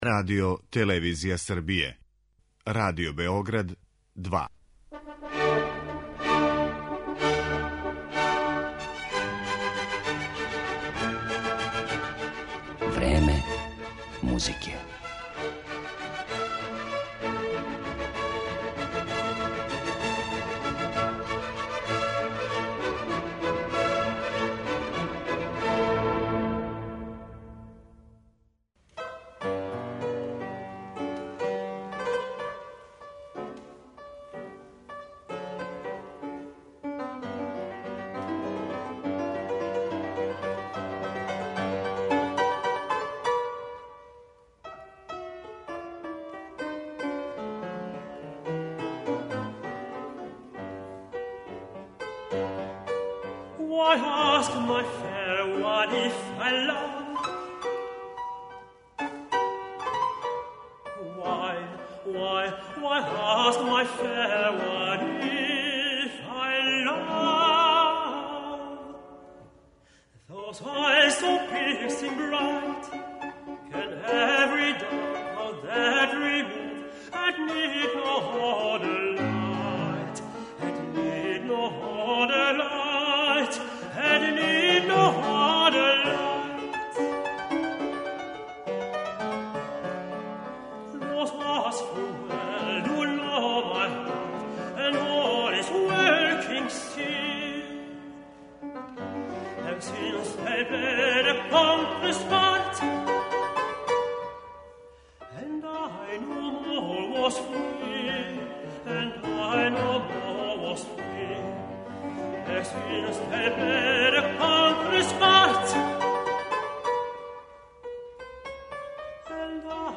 Многима је податак да је Јозеф Хајдн готово читавог живота писао песме за глас и клавир сасвим непознат, јер се овај, не тако мали део његове заоставштине и данас ретко проналази на концертним програмима и снимцима.
белгијски тенор